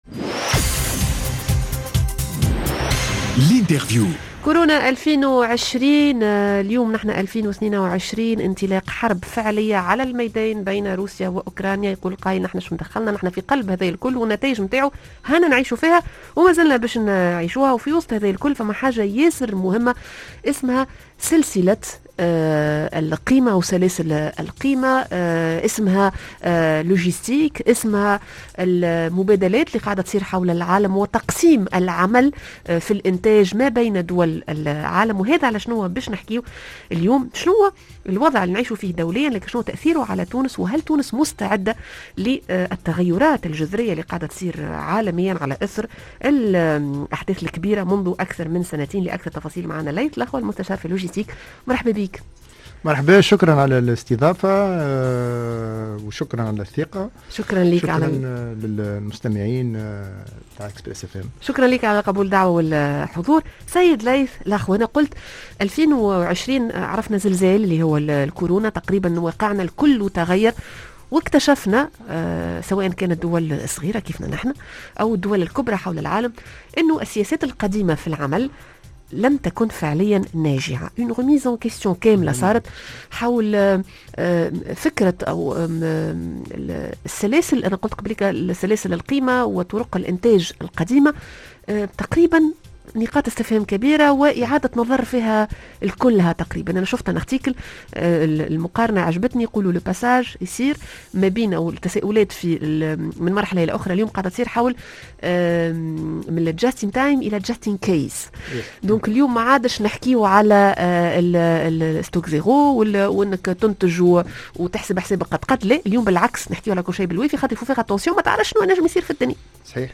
L'interview: بين الكورونا و الحرب ماهي استعدادات تونس؟